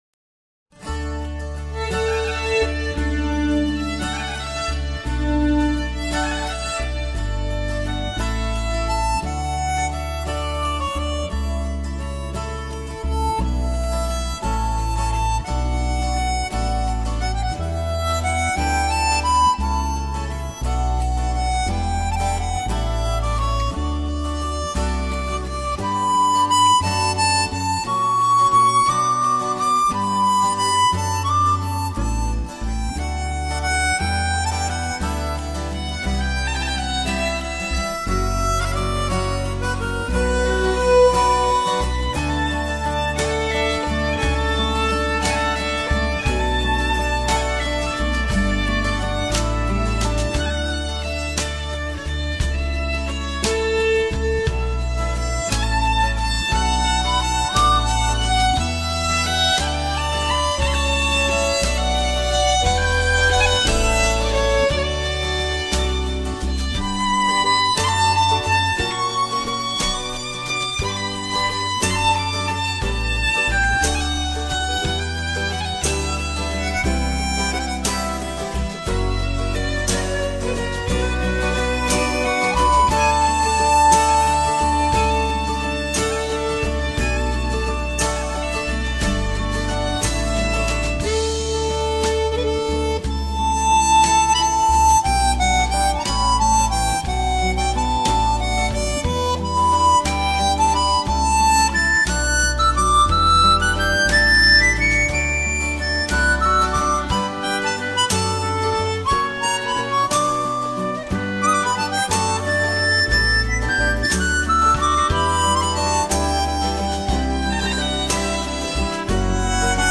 飘然的口琴加天籁的人声,天地之间,
一种沁人心脾的清冽和甘甜,舒缓了紧张的神经,